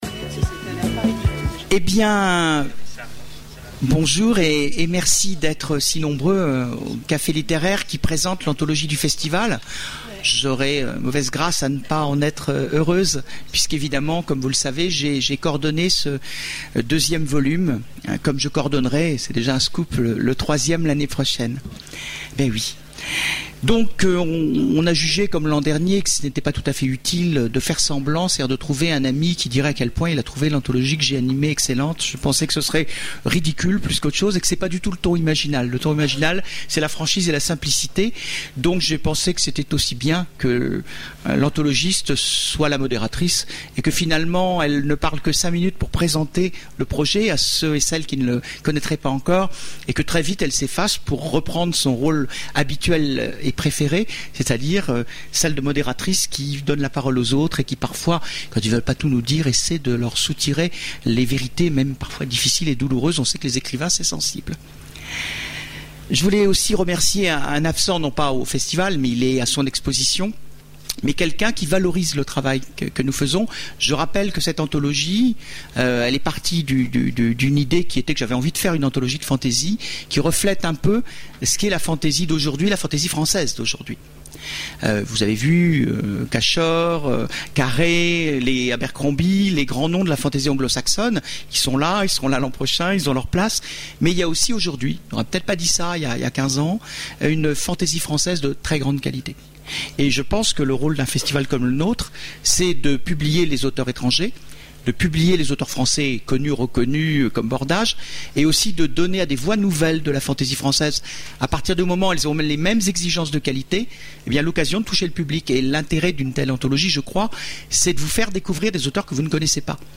Voici l'enregistrement de la conférence sur Magiciennes et Sorciers, l’anthologie du festival ! aux Imaginales 2010